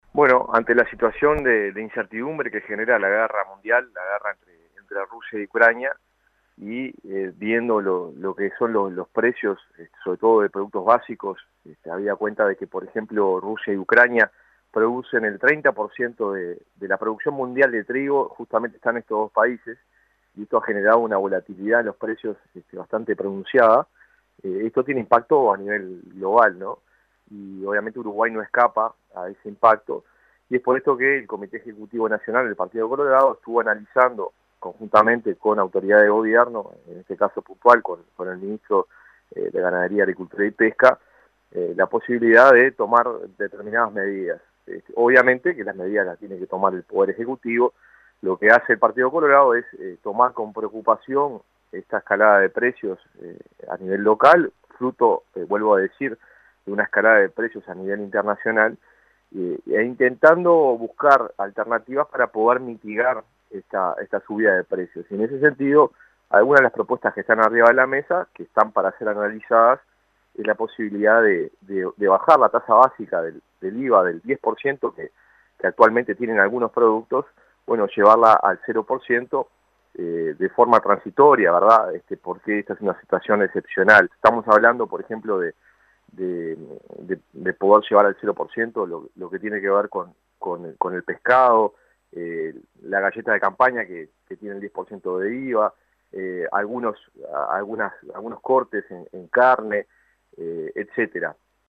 El Partido Colorado estudia proponer «IVA cero» de forma transitoria para amortiguar suba de precios, según confirmo el diputado Conrado Rodríguez a 970 Noticias. El legislador sostuvo que la guerra entre Rusia y Ucrania genera un «impacto global» del cual Uruguay «no escapa».